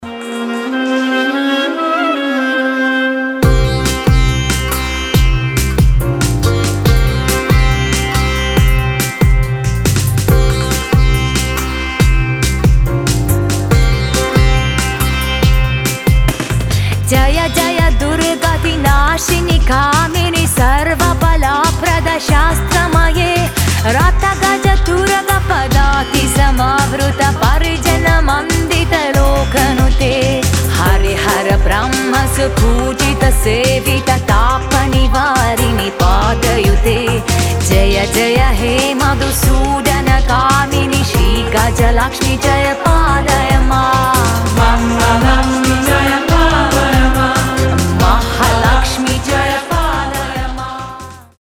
красивые
женский вокал
восточные мотивы
индийские мотивы
мантра